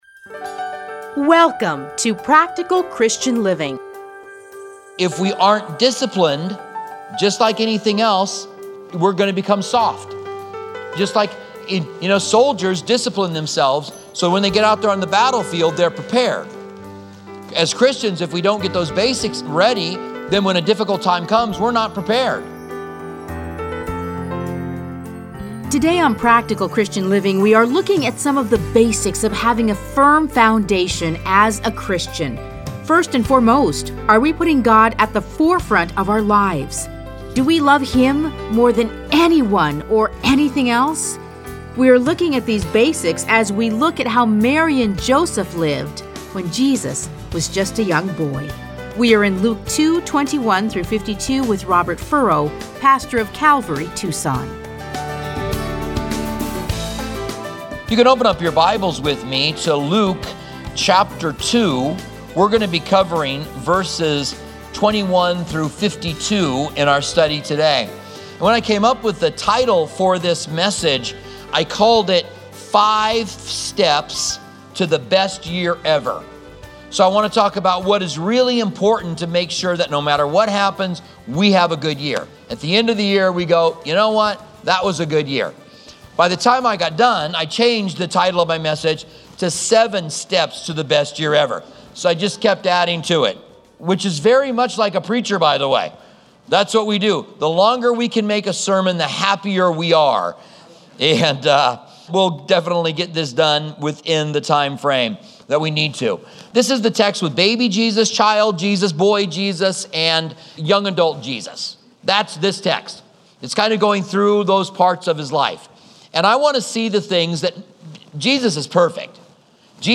Listen to a teaching from Luke 2:21-52.